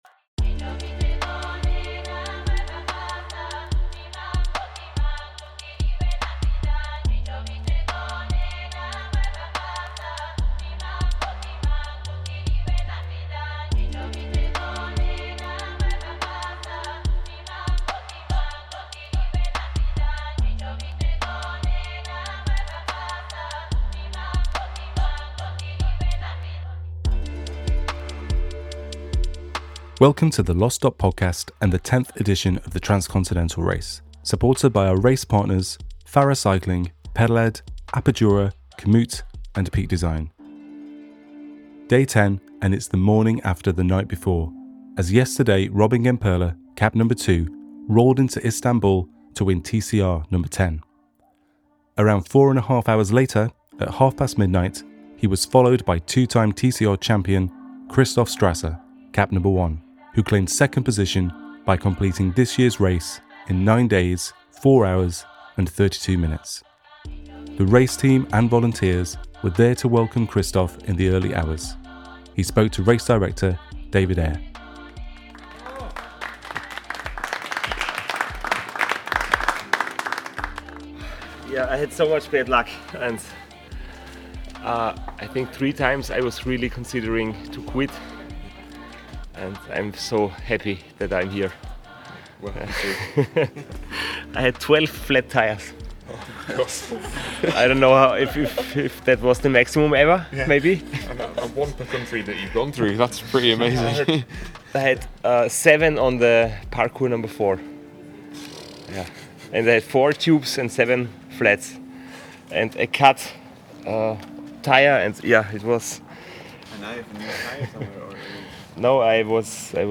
TCRNo10 | Day 10 Aug 01, 2024, 07:34 PM Headliner Embed Embed code See more options Share Facebook X Subscribe TCRNo10 // Day 09 Day 10 at TCRno10 and a missed turn in the final 500m shakes up the final podium positions. Our team at the finish line get a front seat to events as they unfold, and friends and family waiting at the Finish lend an inside perspective on the riders arriving in Istanbul.